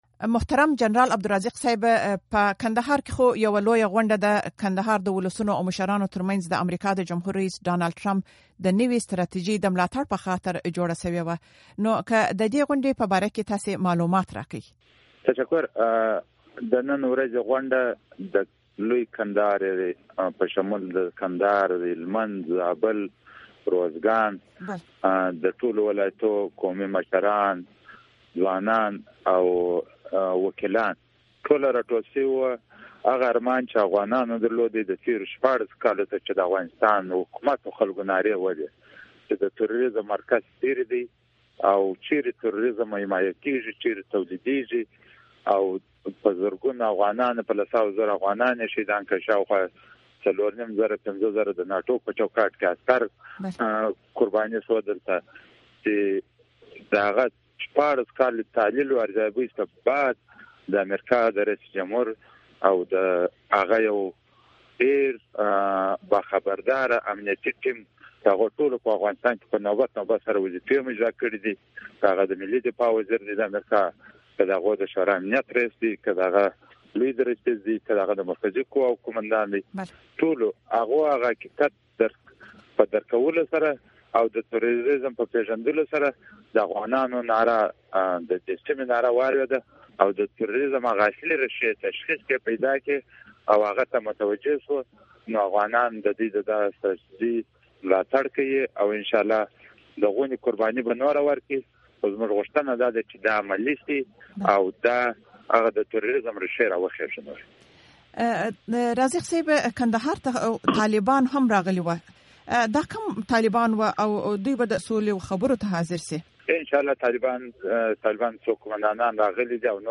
مرکې
د جنرال عبدالرازق سره مرکه